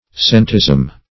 Centesm \Cen"tesm\ (s[e^]n"t[e^]z'm)